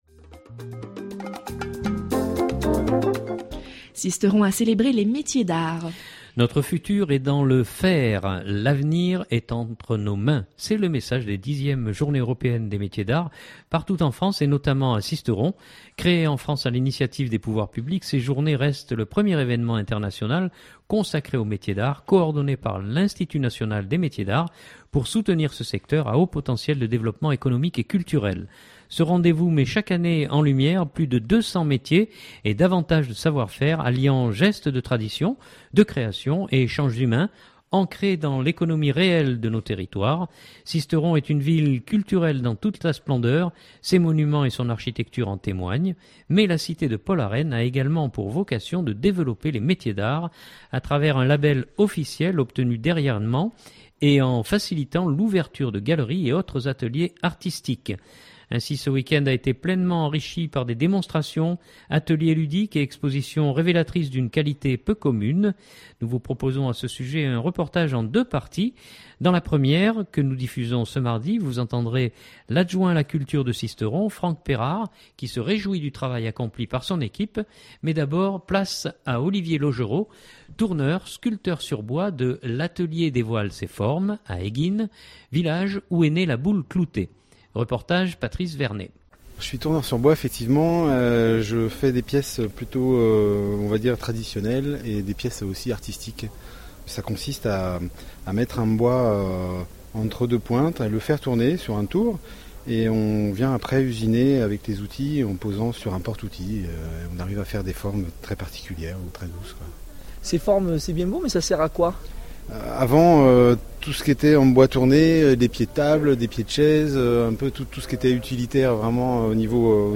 Ainsi ce week-end a été pleinement enrichi par des démonstrations, ateliers ludiques et expositions révélatrices d’une qualité peu commune. Nous vous proposons à ce sujet, un reportage en deux parties. Dans la première que nous diffusons ce mardi, vous entendrez l’Adjoint à la Culture de Sisteron, Franck Pérard qui se réjouit du travail accompli par son équipe.